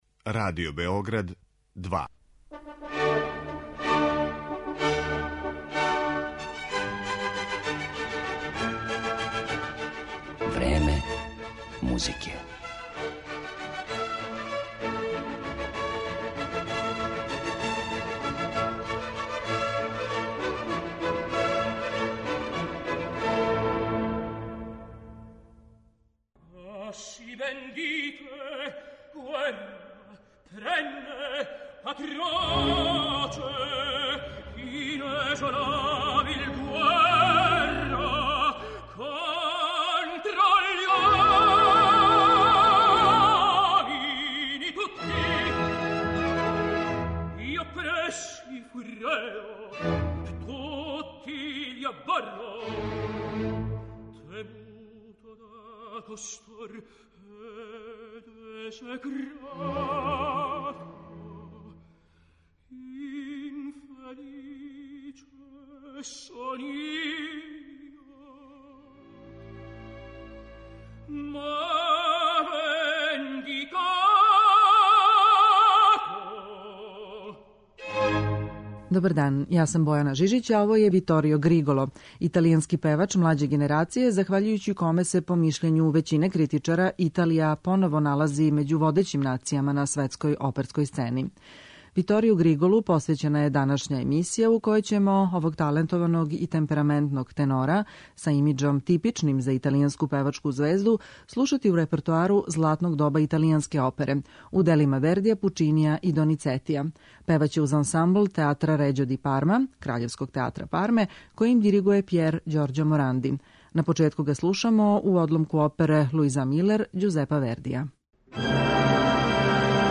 италијанског лирског тенора